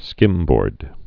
(skĭmbôrd)